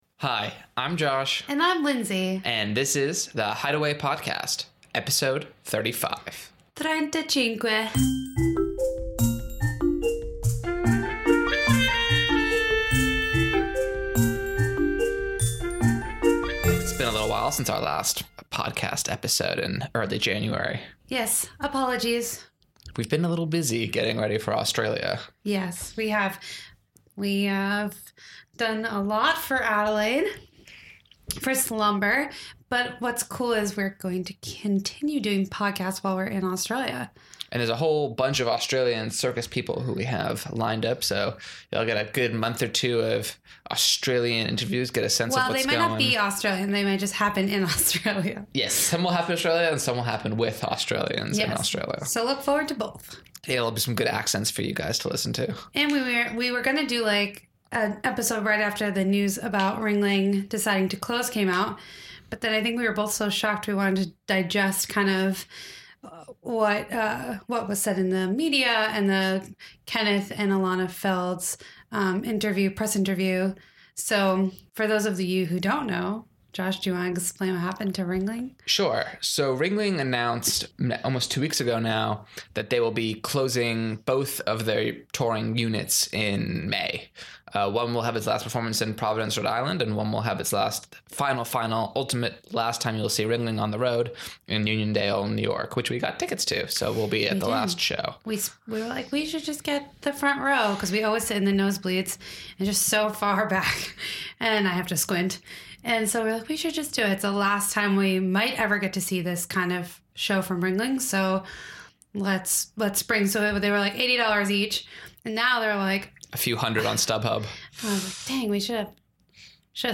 Episode 35- Ringling's Closure and an interview